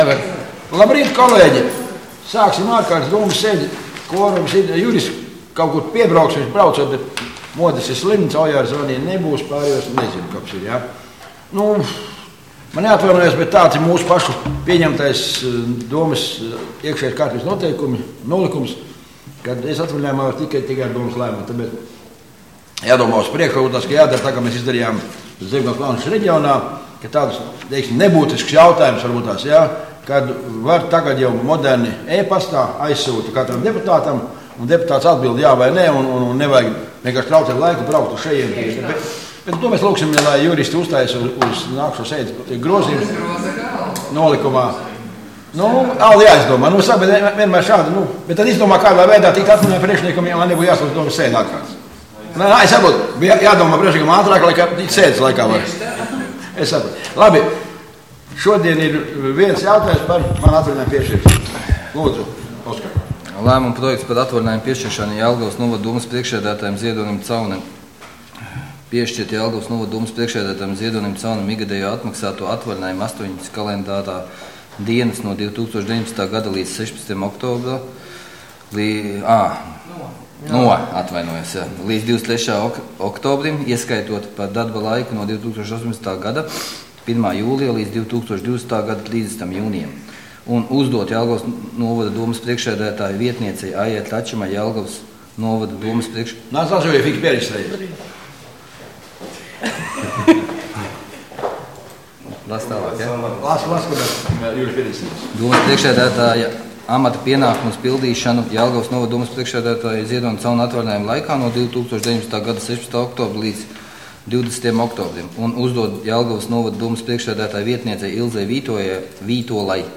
Domes ārkārtas sēde Nr. 17